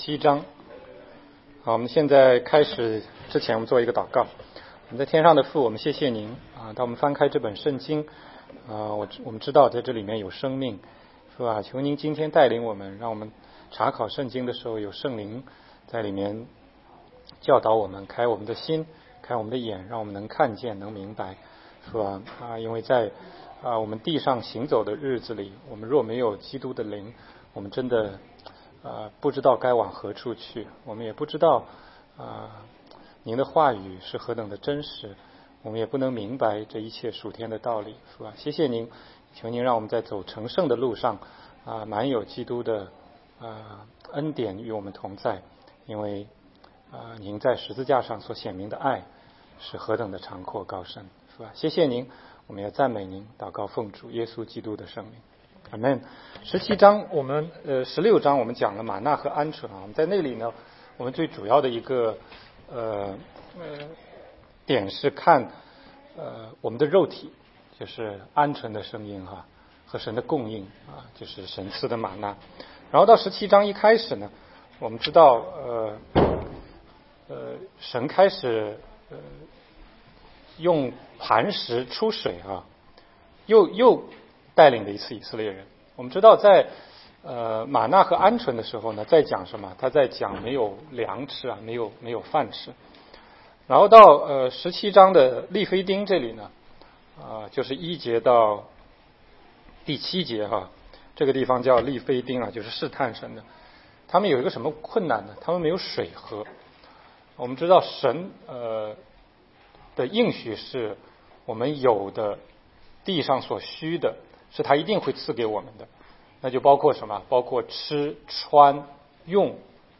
16街讲道录音 - 靠神得胜